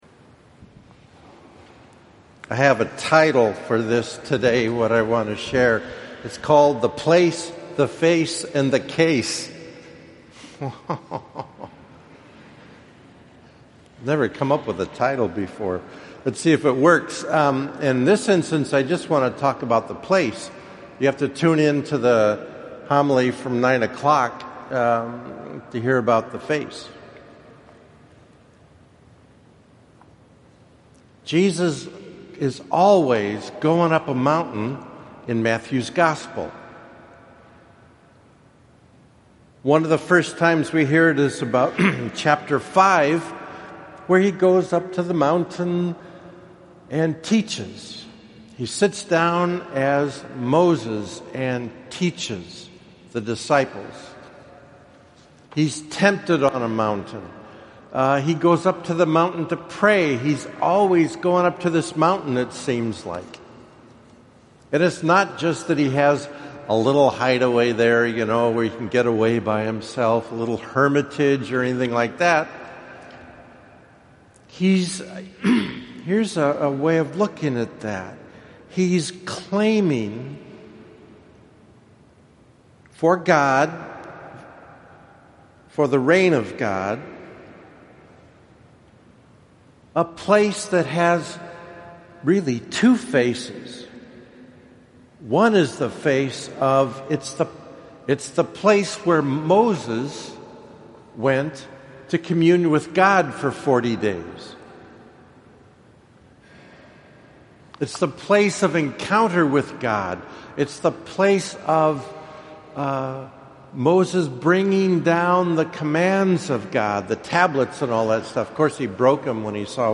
Each word in Greek contributes to the overall meaning of the Transfiguration. Here is the second and different audio homily.